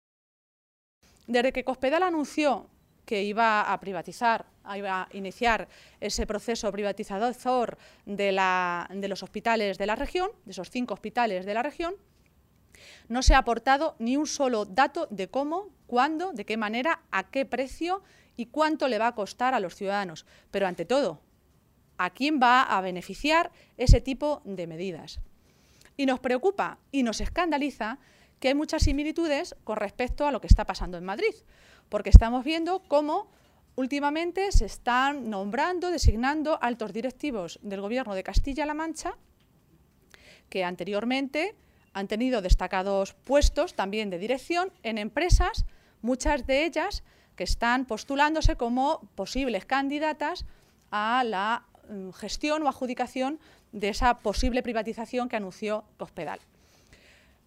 Así lo señalaba Maestre en una comparecencia ante los medios de comunicación, en Toledo, esta tarde, minutos antes de la reunión de la dirección regional del PSOE castellano-manchego.